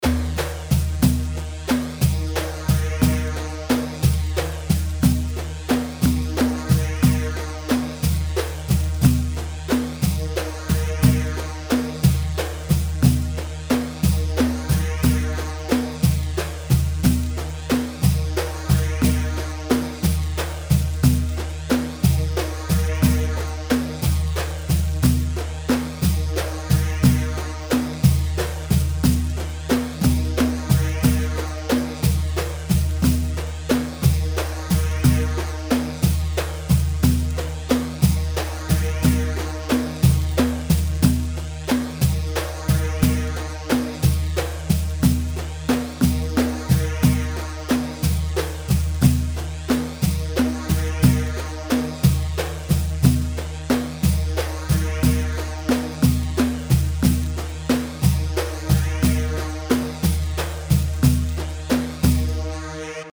3ashoury 3/4 180 عاشوري
Loop Session